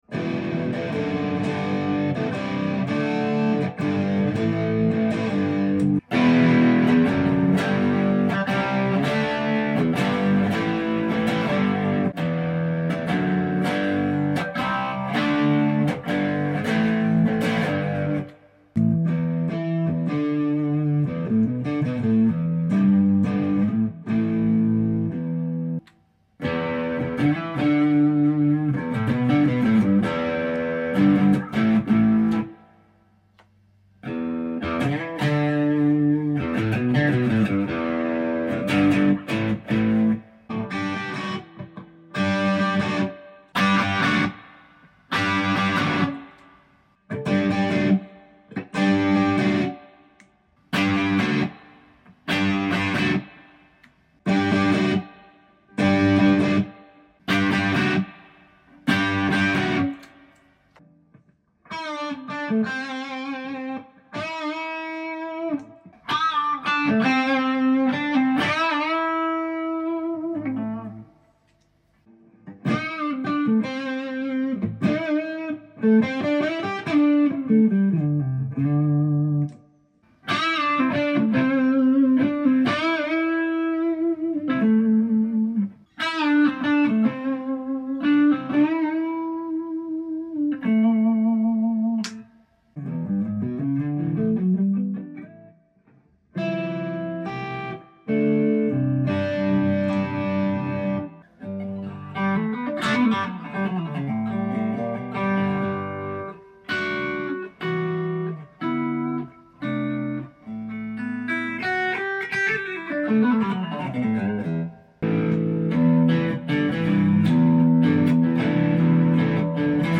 This video is all about the sound of ESP LTD EC-256 after a complete overhaul. From the Setup Deluxe for flawless playability to the full Rewire and Hardware upgrade, this guitar now sounds and feels like a whole new instrument.